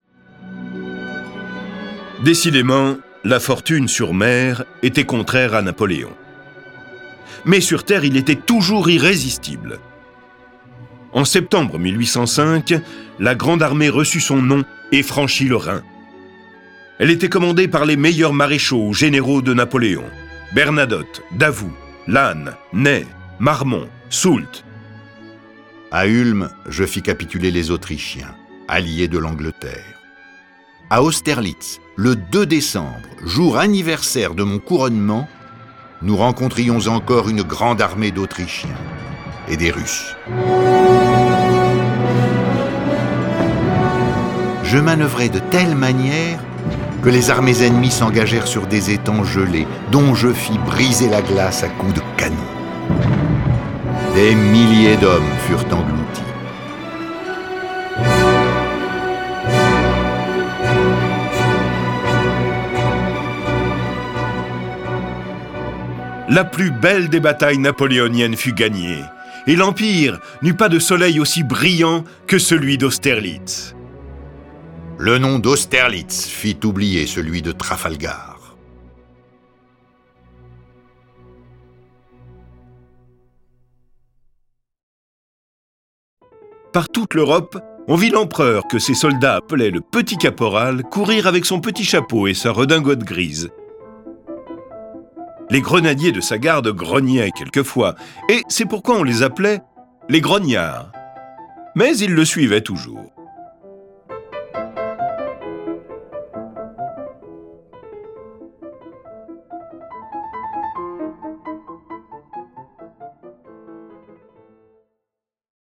Cette version sonore de ce récit est animée par dix voix et accompagnée de plus de trente morceaux de musique classique.